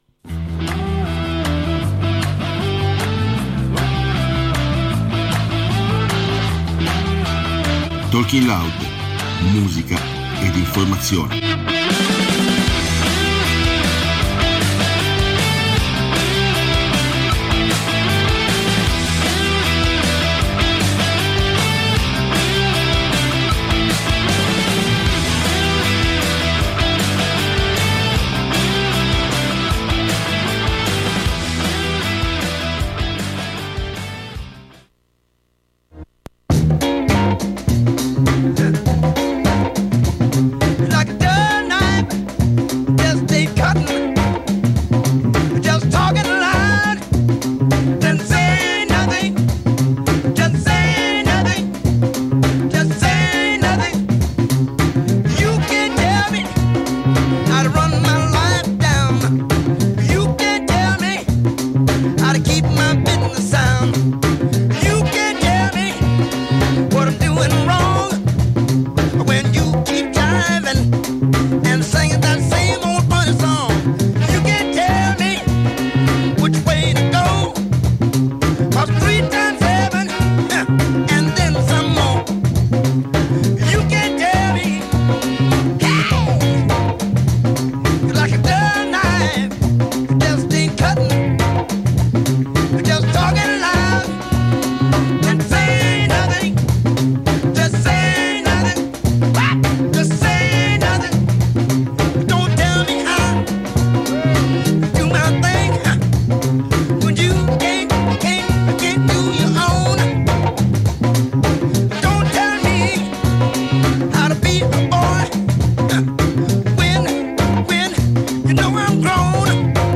Questa la scaletta, che ha accompagnato e cadenzato – come al solito – gli interventi in voce!